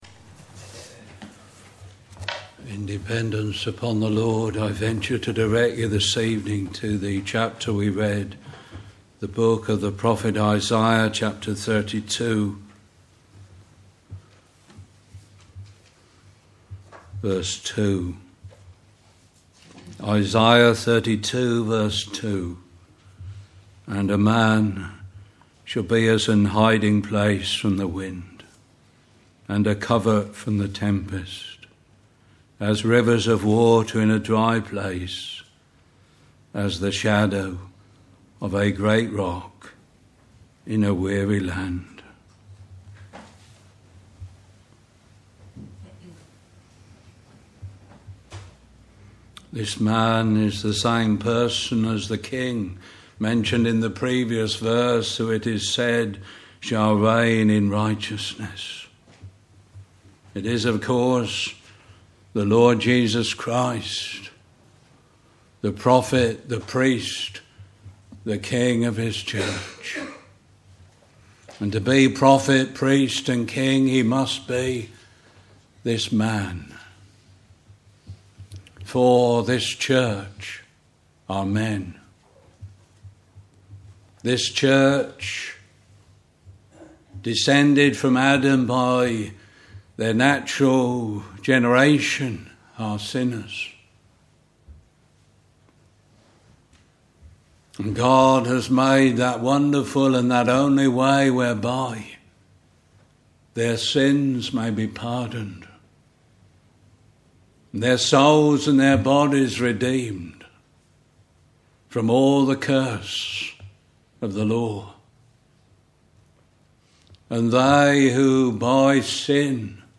Harvest Thanksgiving